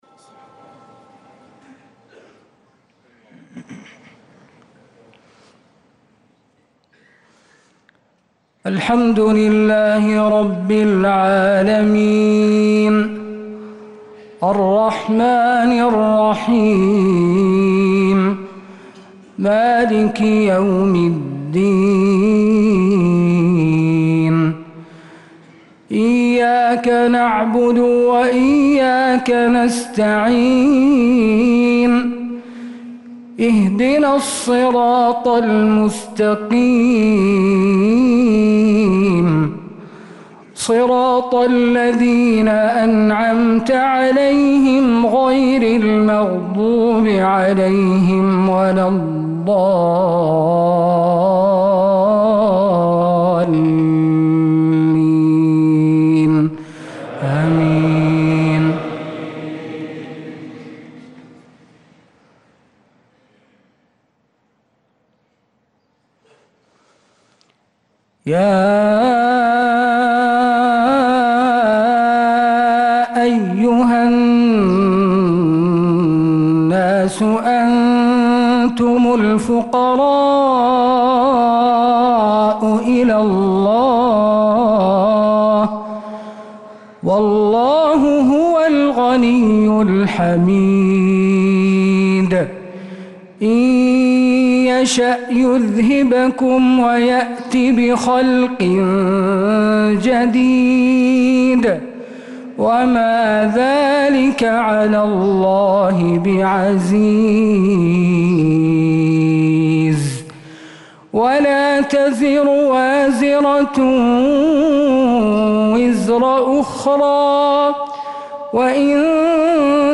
صلاة المغرب
تِلَاوَات الْحَرَمَيْن .